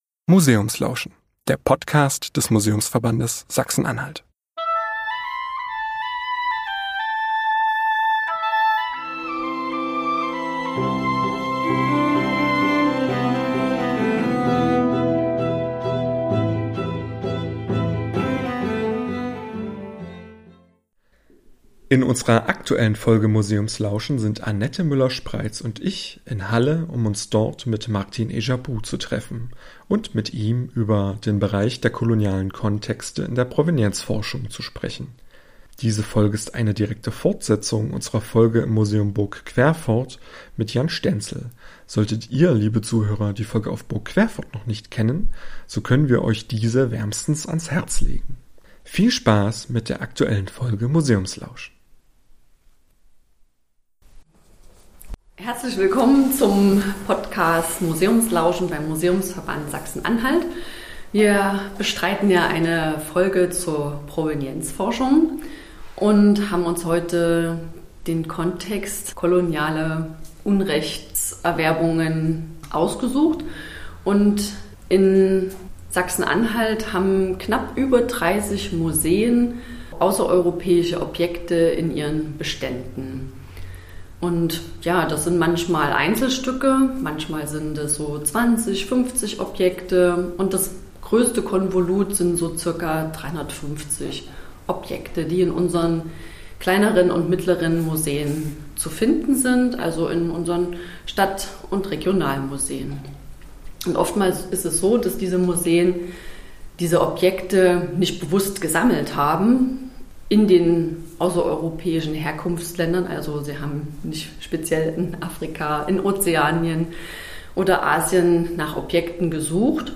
Gastinterview